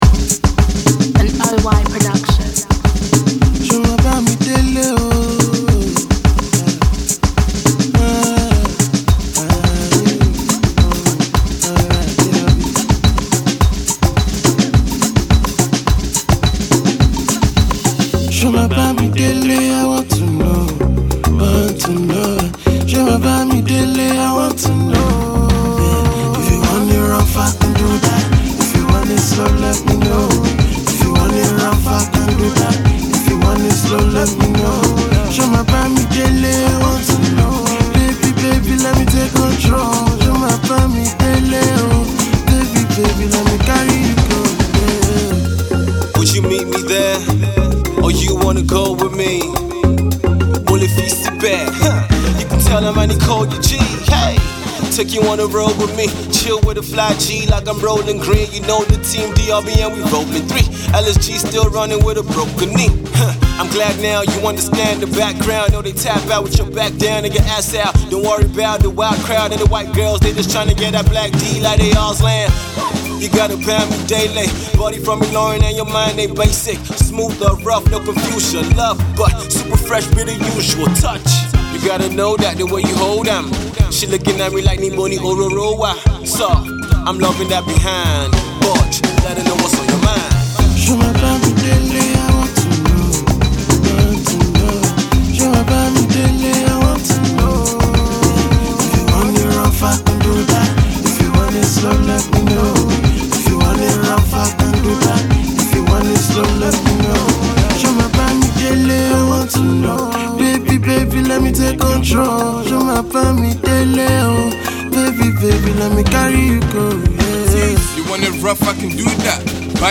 Brazilian Samba
rapping and singing